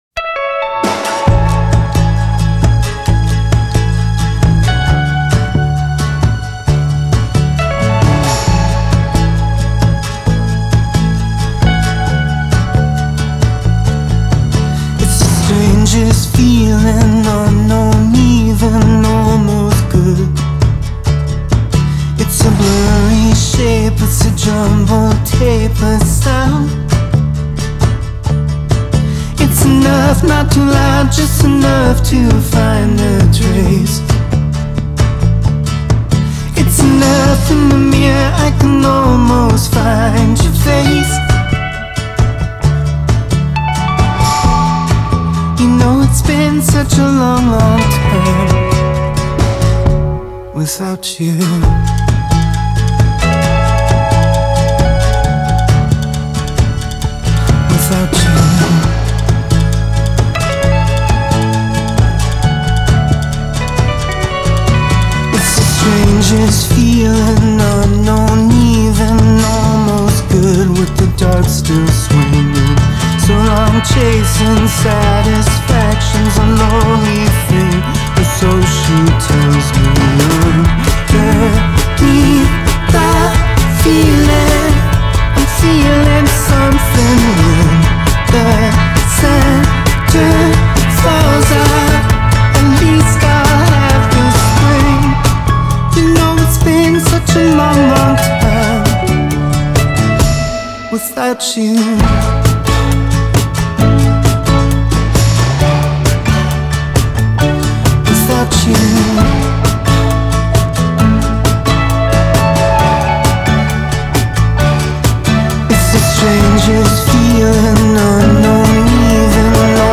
Genre : Alt. Rock